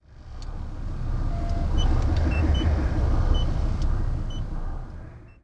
tim_comp_hum3.wav